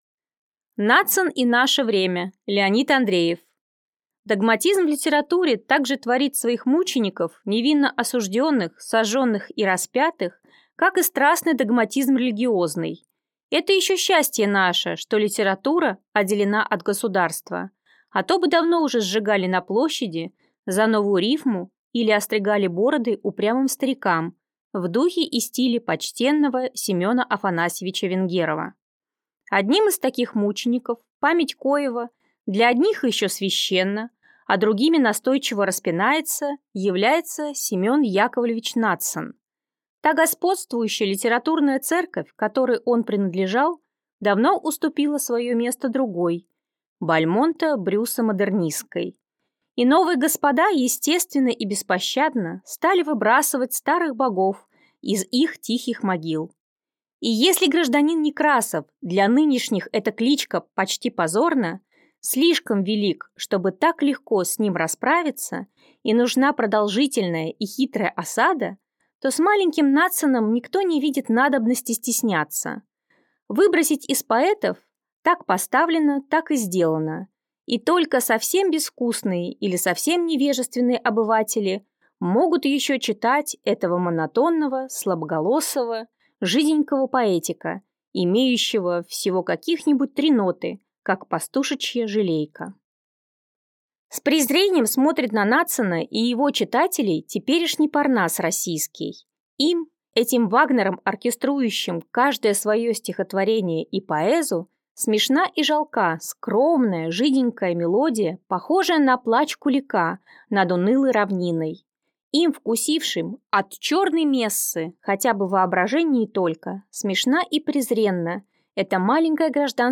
Аудиокнига Надсон и наше время | Библиотека аудиокниг